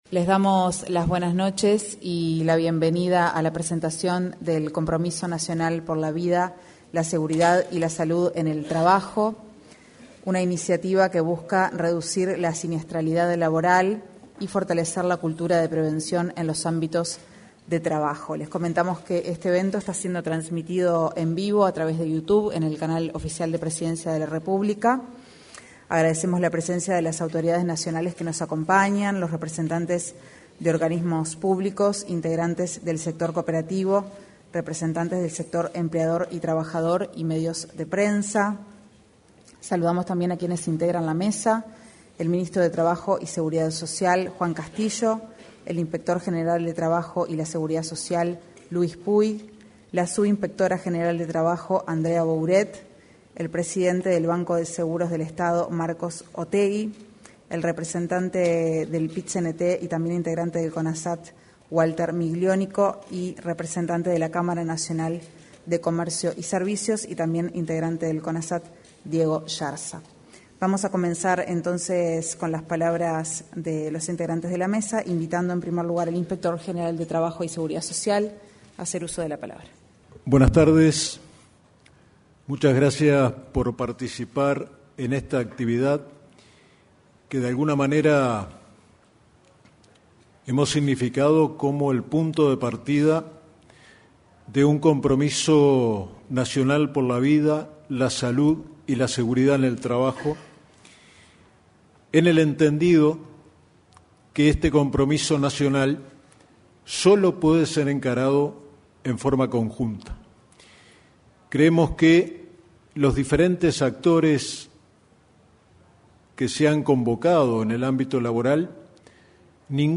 Participaron: el inspector general de Trabajo y Seguridad Social, Luis Puig; el presidente del Banco de Seguros del Estado, Marcos Otheguy, y el ministro de Trabajo y Seguridad Social, Juan Castillo.